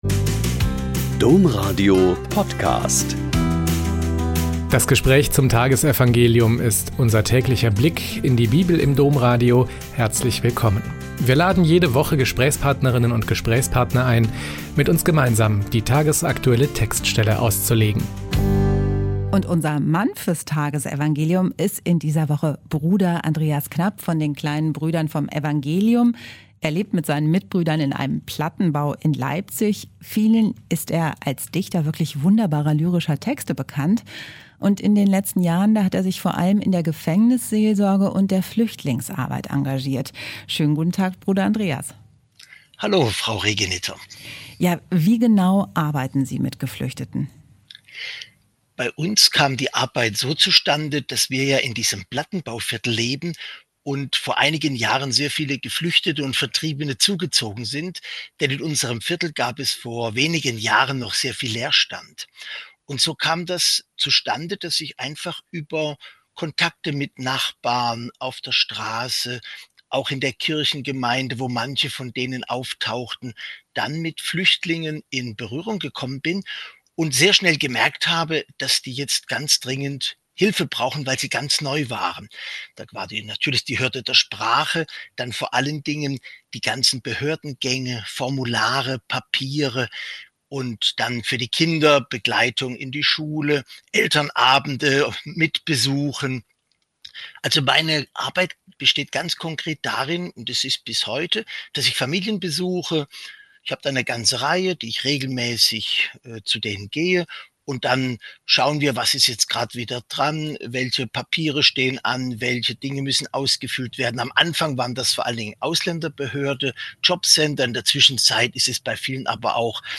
Mt 20,20-28 - Gespräch mit Bruder Andreas Knapp ~ Blick in die Bibel Podcast